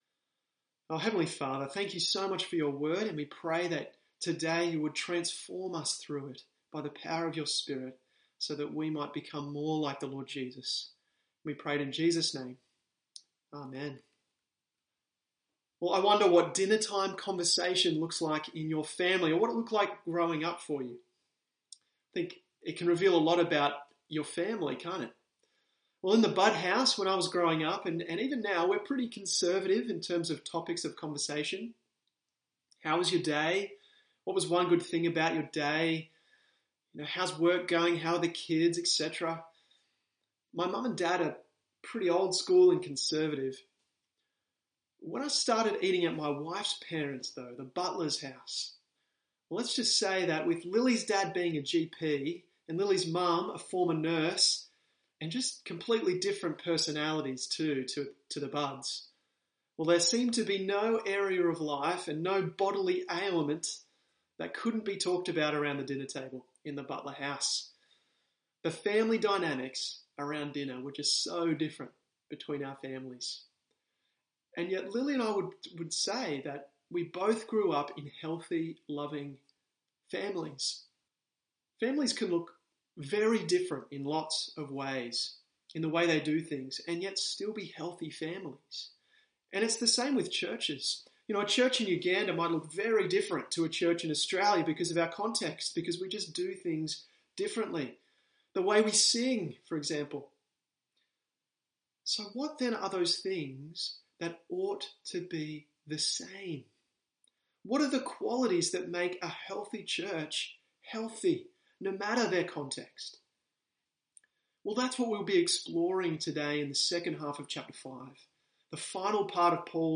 or click the ‘Download Sermon’ button above or press ‘play’ in the audio bar for an audio-only version